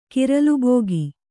♪ kiralu bōgi